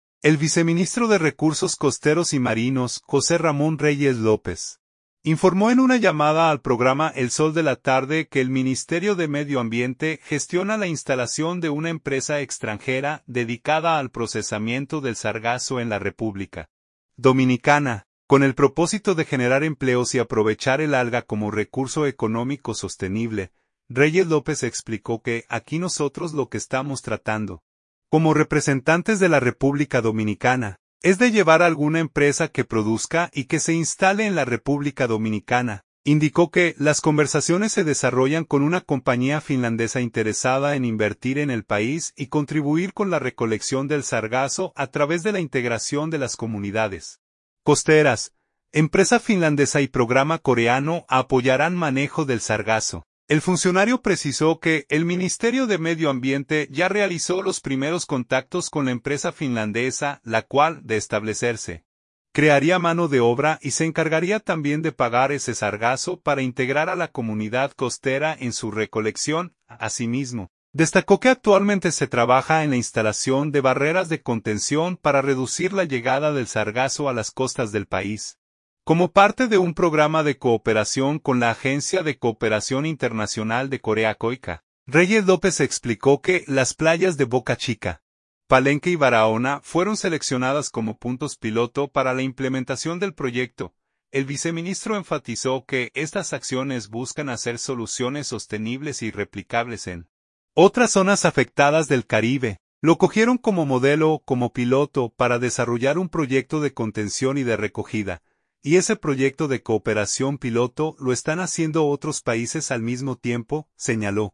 El viceministro de Recursos Costeros y Marinos, José Ramón Reyes López, informó en una llamada al programa El Sol de la Tarde que el Ministerio de Medio Ambiente gestiona la instalación de una empresa extranjera dedicada al procesamiento del sargazo en la República Dominicana, con el propósito de generar empleos y aprovechar el alga como recurso económico sostenible.